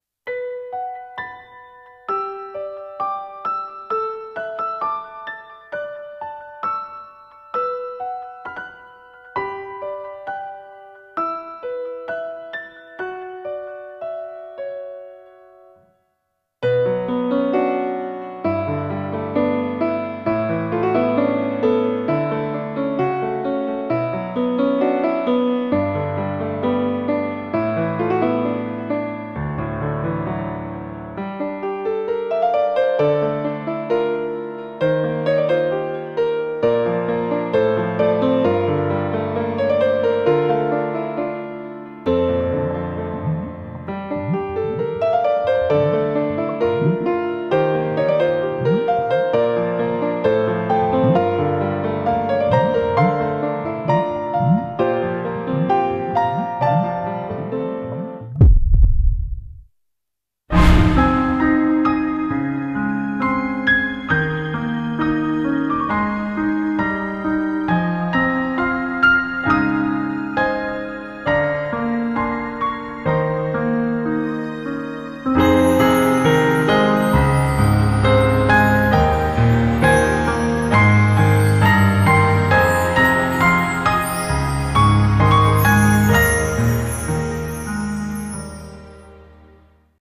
CM風声劇「勿忘草」